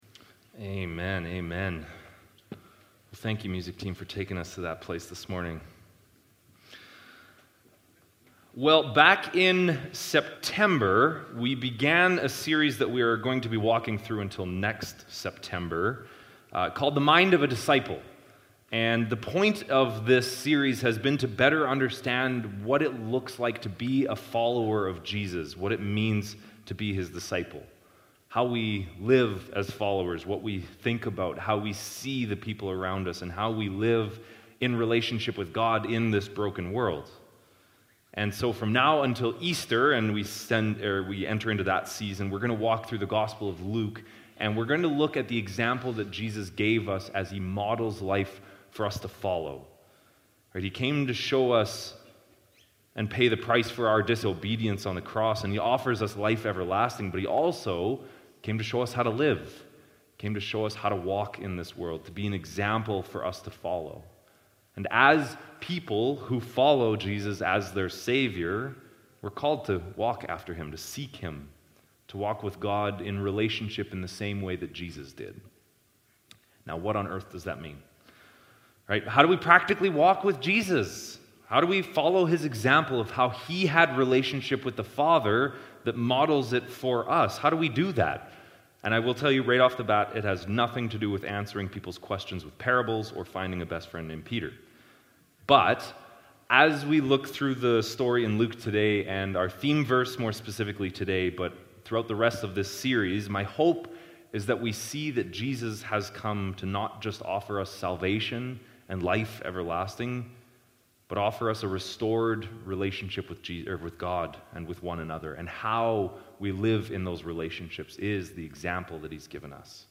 Sermons | Leduc Fellowship Church